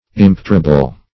Search Result for " impetrable" : The Collaborative International Dictionary of English v.0.48: Impetrable \Im"pe*tra*ble\a. [L. impetrabilis: cf. F. imp['e]trable.
impetrable.mp3